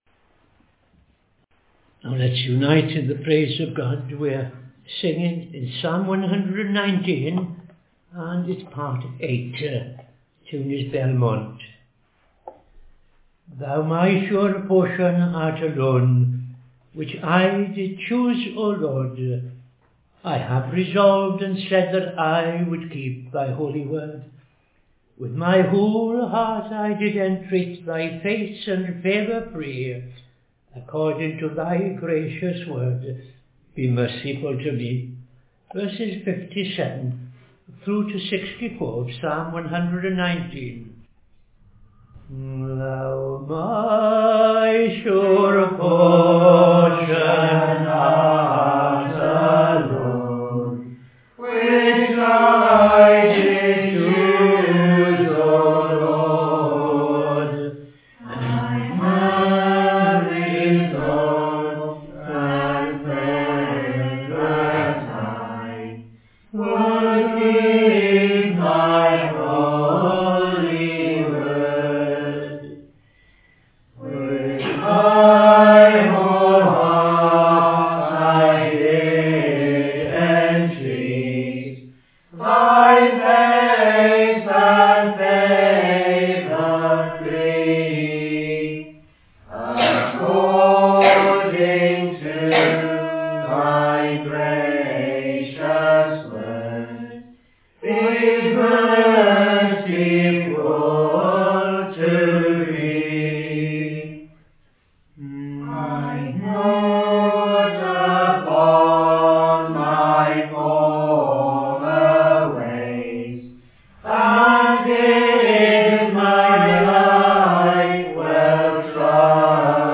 5.00 pm Evening Service Opening Prayer and O.T. Reading I Chronicles 16:1-43
Psalm 4:1-8 ‘Give ear unto me when I call,’ Tune Ellacombe